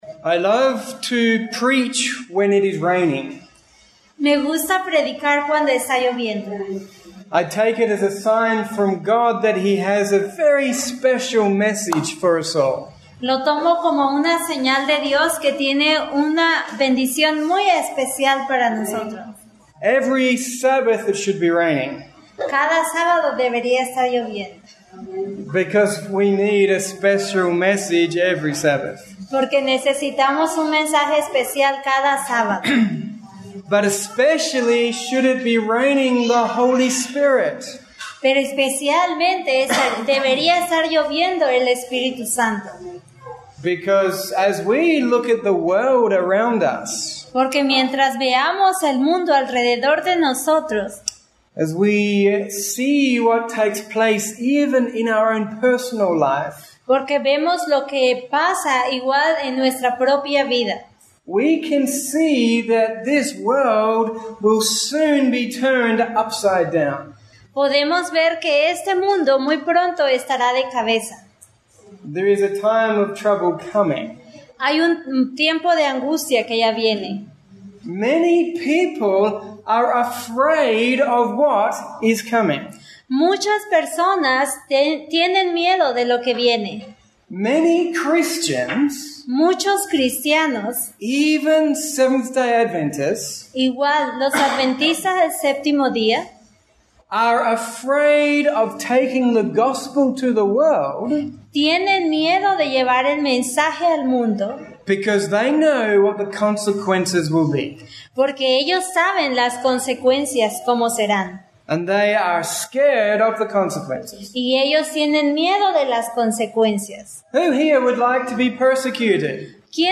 A message of hope and comfort for those confused by life's trials; for those who think that God has left them. ESP: Un mensaje de esperanza y comfortamiento para aquellos que estan confundidos por las luchas de la vida; y aquellos que piensan que Dios los a abandonados. 11th June 2016, Oaxaca, Mexico. Con traduccion en Espanol.